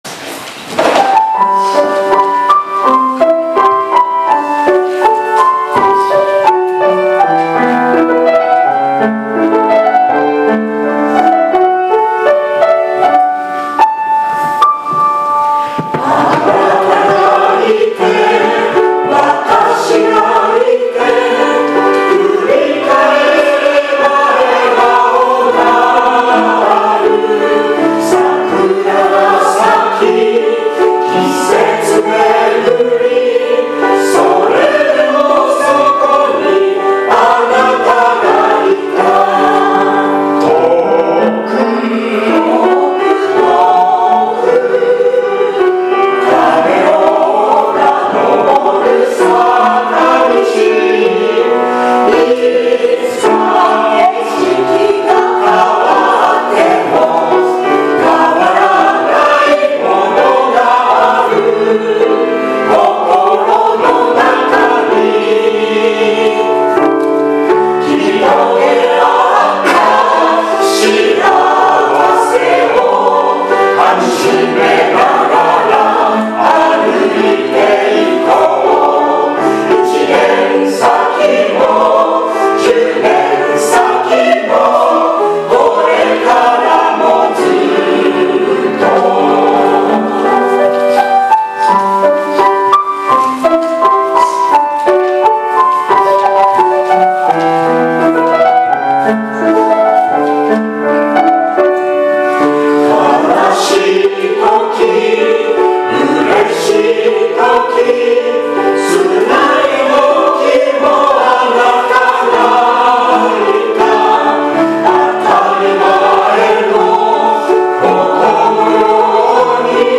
聖歌隊奉唱・献花
「変わらないもの」礼拝堂前部録音